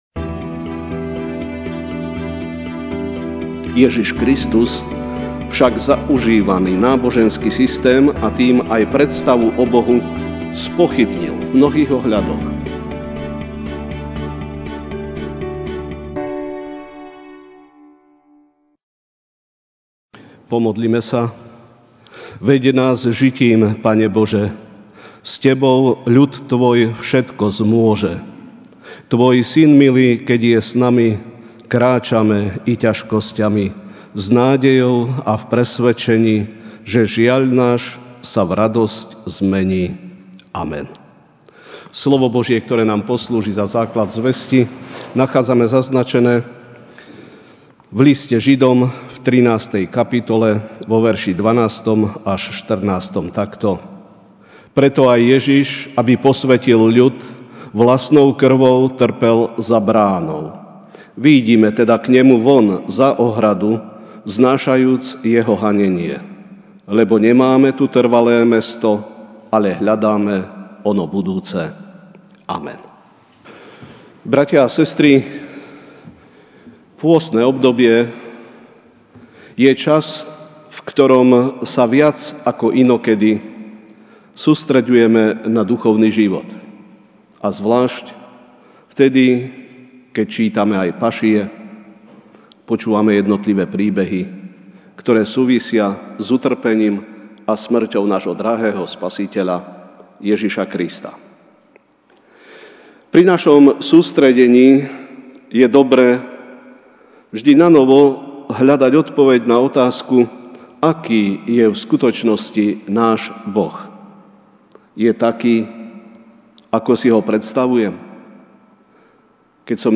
apr 07, 2019 Predstavy o Bohu MP3 SUBSCRIBE on iTunes(Podcast) Notes Sermons in this Series Večerná kázeň: Predstavy o Bohu (Žd 13, 12-14) Preto aj Ježiš, aby posvätil ľud vlastnou krvou, trpel za bránou.